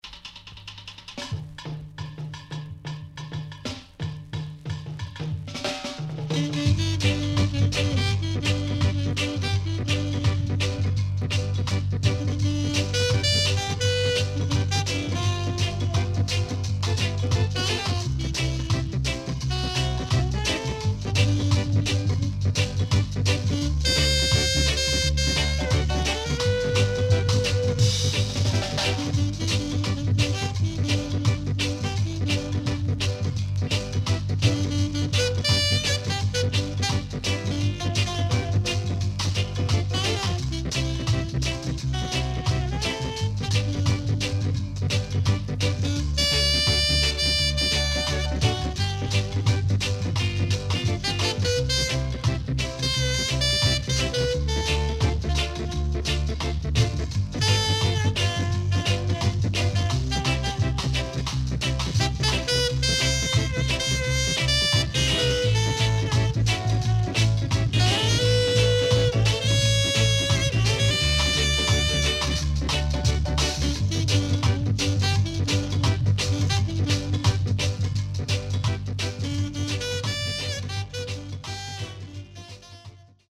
CONDITION SIDE A:VG(OK)〜VG+
SIDE A:プレス起因により全体的にチリノイズ入ります。